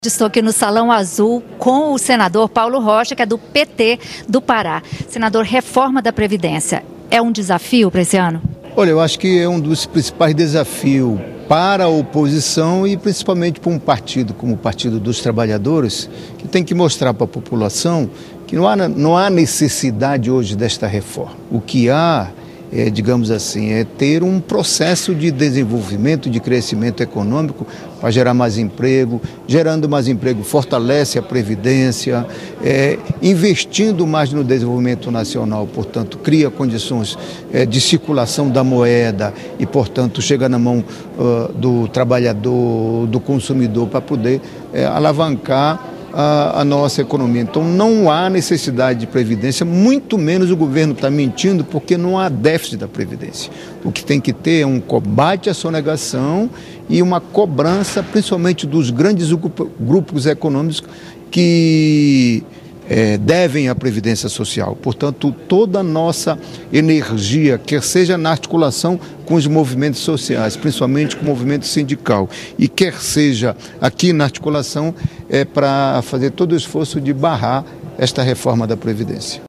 Não há necessidade de reforma da Previdência nesse momento, afirmou o senador Paulo Rocha (PT-PA). Em entrevista